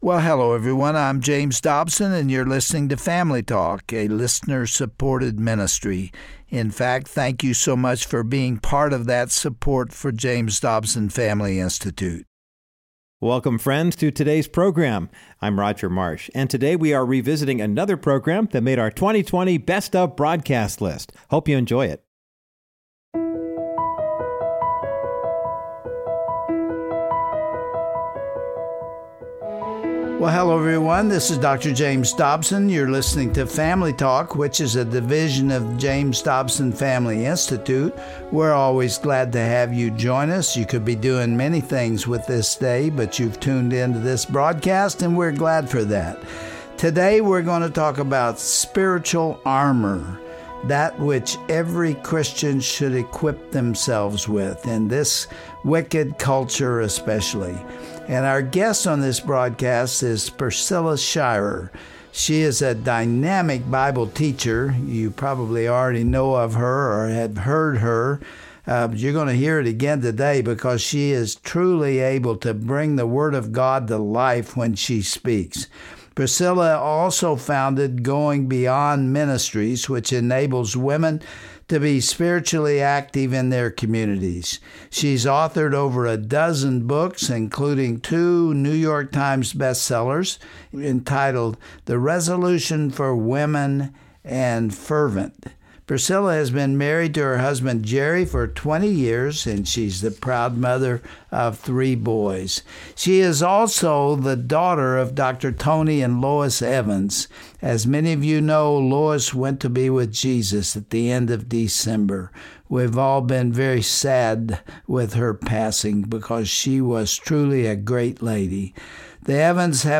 When we stumble in our Christian walk, we often blame others instead of recognizing the devil's schemes against us. Popular author and evangelist Priscilla Shirer talks about the spiritual warfare that surrounds us every day.